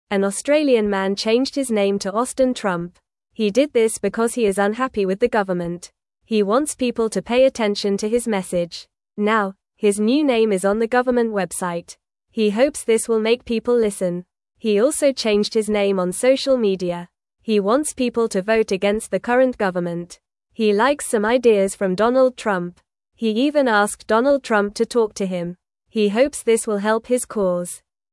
Fast
English-Newsroom-Beginner-FAST-Reading-Man-Changes-Name-to-Austin-Trump-for-Attention.mp3